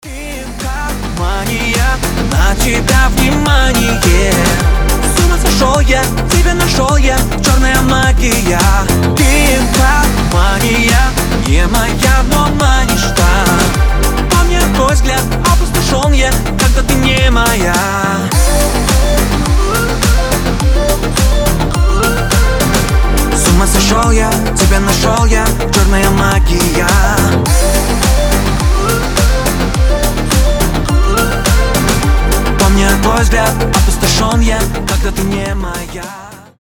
• Качество: 320, Stereo
мужской голос
громкие
зажигательные